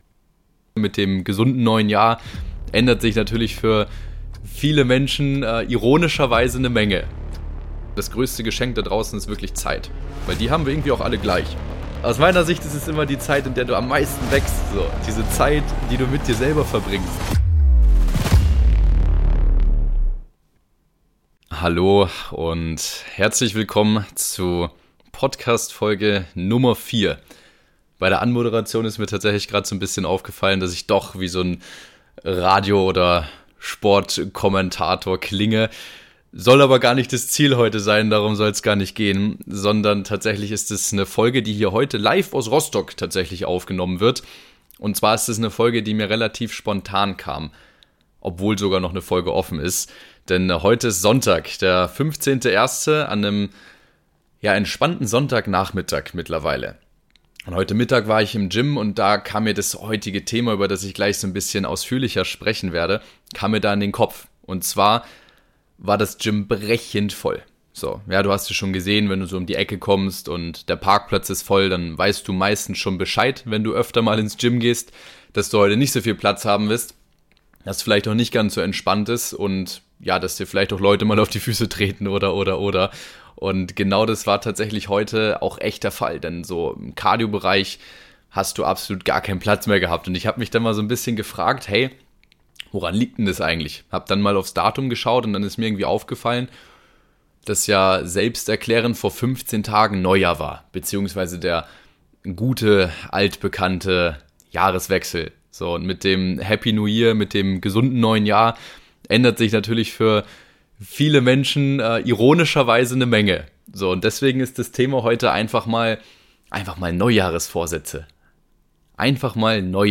In Folge 4 bekommst du Insider über die aktuelle Lage im Gym, mein - in dieser Folge - absolut amateurhaftes Setup und meine Sichtweise auf Neujahresvorsätze gepaart mit den Top 3 Vorsätzen der Deutschen für das Jahr 2023.